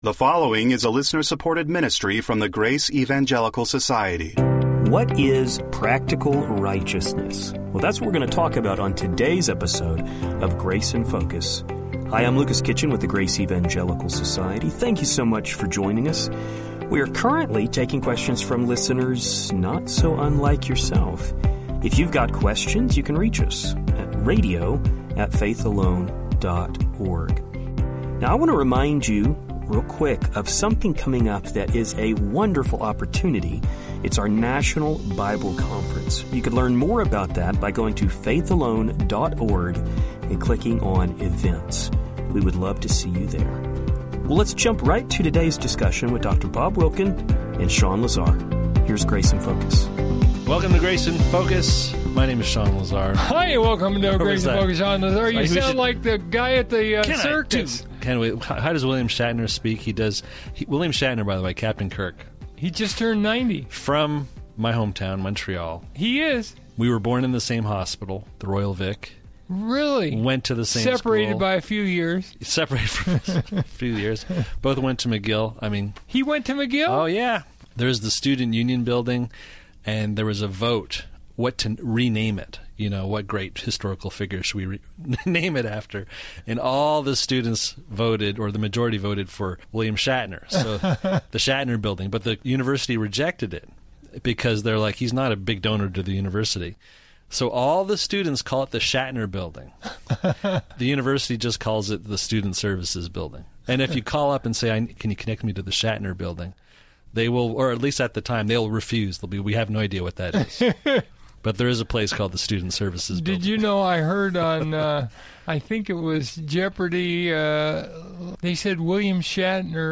We will hear the guys draw a distinction between practical righteousness and positional righteousness. They will address positional righteousness by looking at Gen 15;1-5, Romans 4:1-3, and Gal 3:6-14. Then they will address practical righteousness in light of James 2, 1 Jn 1:7-10, and more.